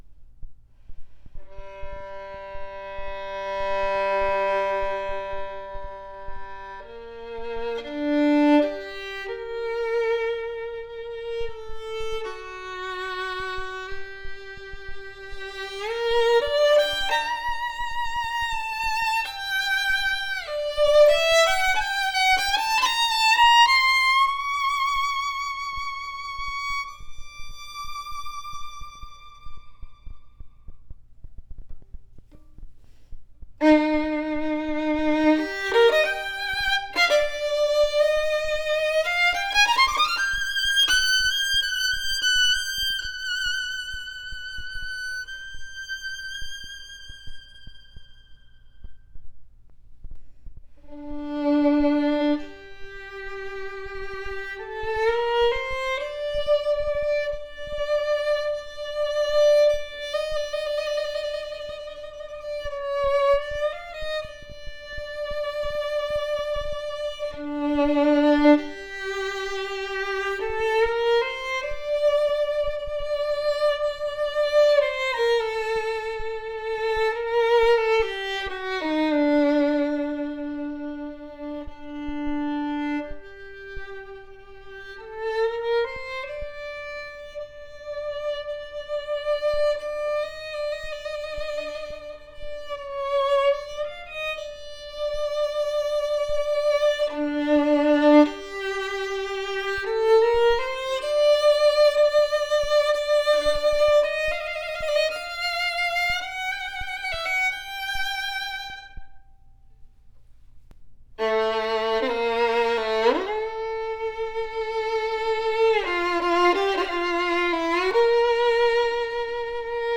A superior ringing and projective violin with depth, robust and thick tone as audio clip represents! Deep and loud tone!
A fundamentally strong sounding violin has a thick tonal texture, yet still open and projective for easy playability.